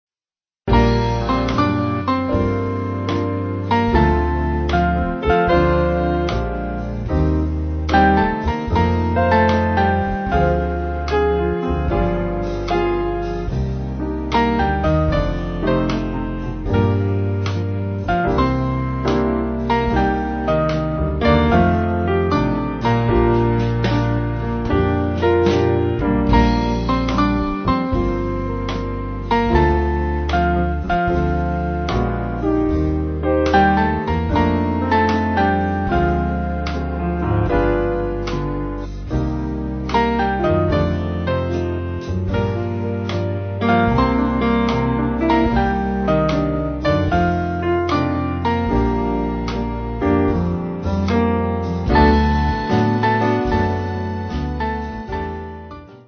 Small Band
(CM)   Bb